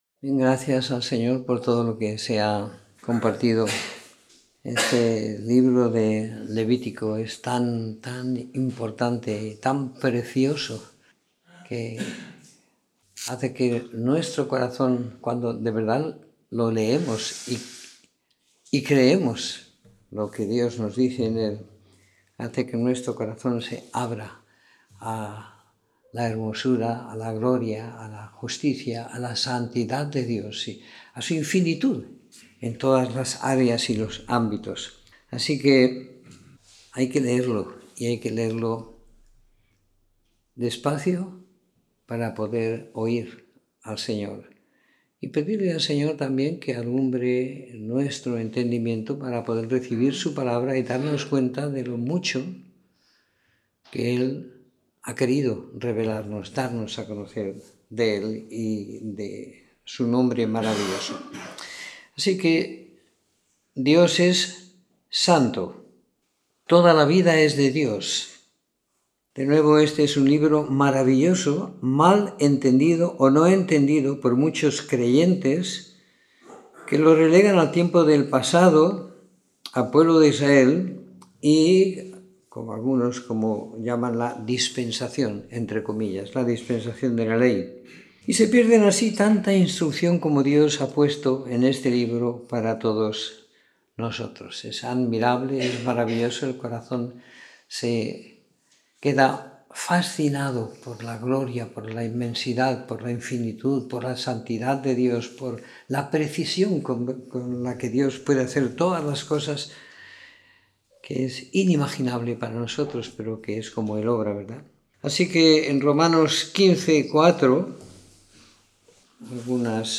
Escuchar la Reunión / Descargar Reunión en audio Comentario en el libro de Levítico del capítulo 1 al 15 siguiendo la lectura programada para cada semana del año que tenemos en la congregación en Sant Pere de Ribes.